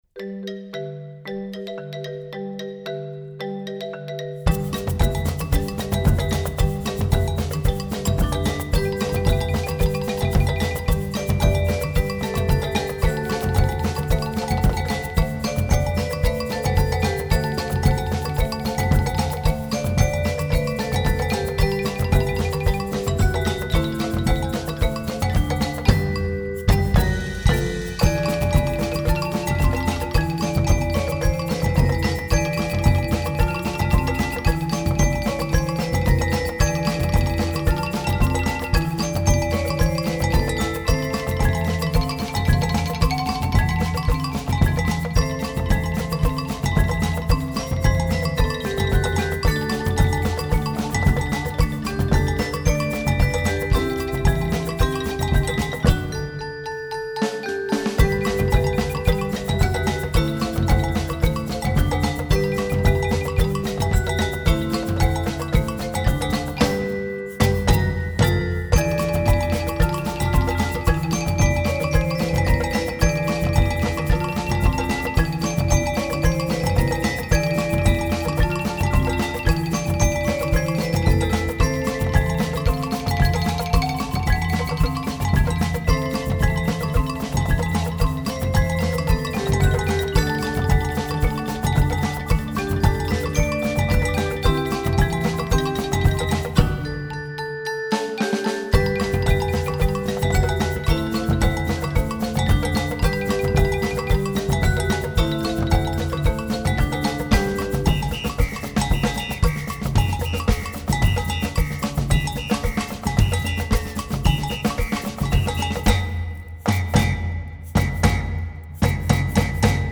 Voicing: Percussion Parts